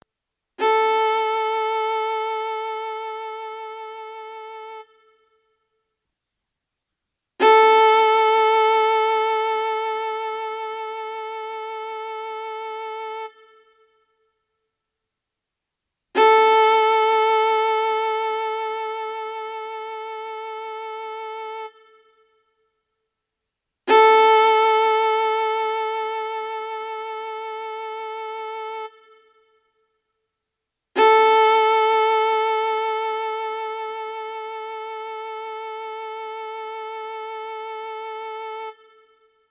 Wenn Sie auf die folgenden Links klicken, hören Sie, wie die Saiten klingen und können ihre Viola danach stimmen:
A-Saite (mp3):
viola_a.mp3